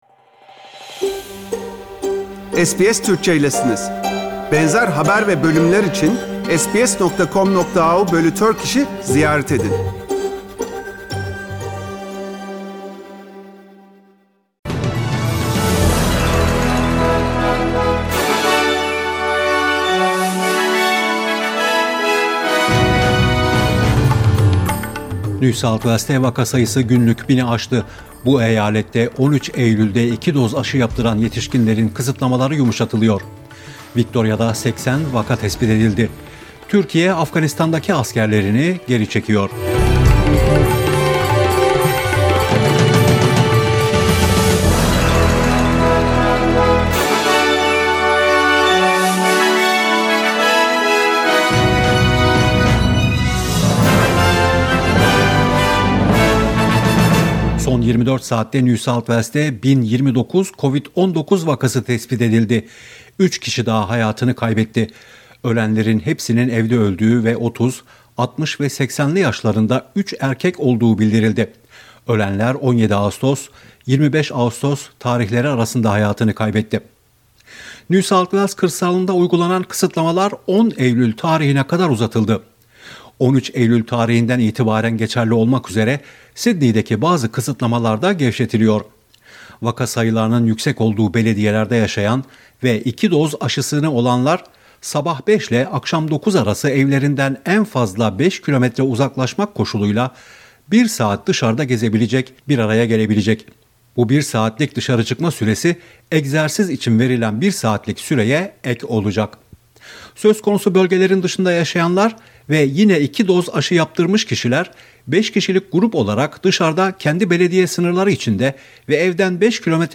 SBS Türkçe Haberler 26 Ağustos